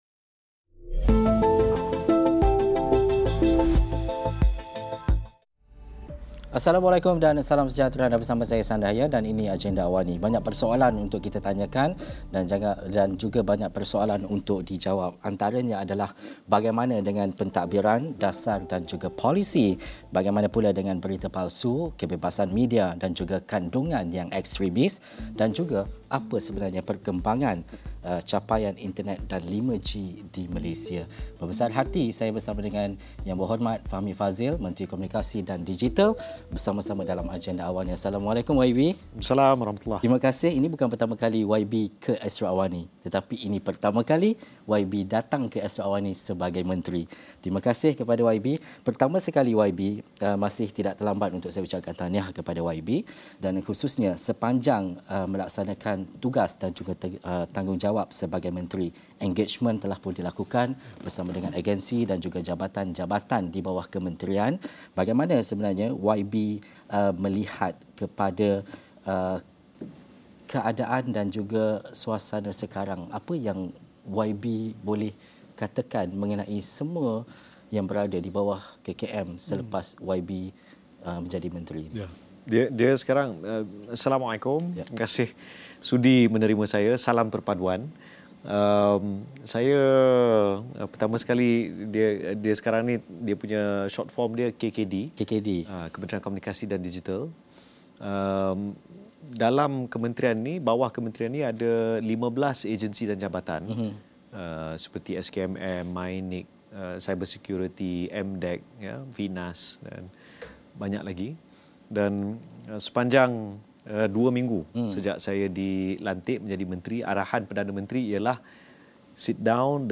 Ikuti temu bual khas bersama Menteri Komunikasi dan Digital, YB Fahmi Fadzil untuk berdiskusi mengenai teknologi 5G, perkhidmatan telekomunikasi negara dan usaha bendung berita palsu dalam Agenda Awani 9 malam ini.